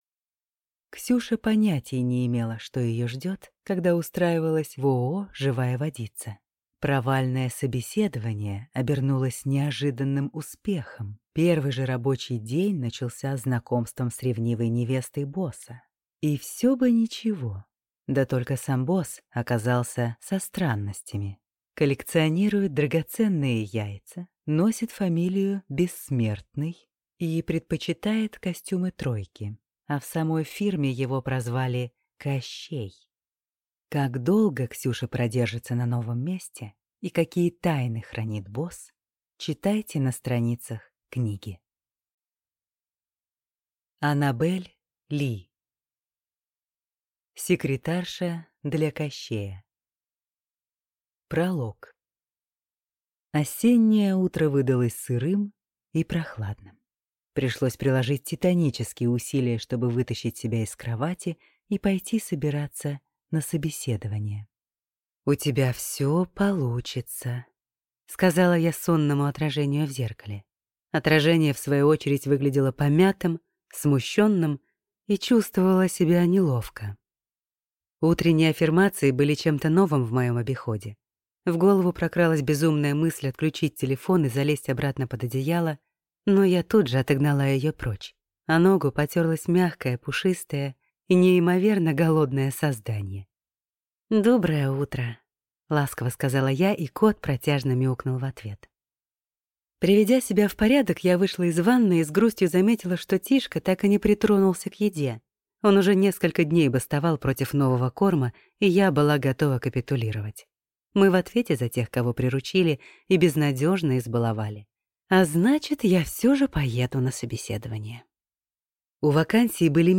Аудиокнига Секретарша для Кощея | Библиотека аудиокниг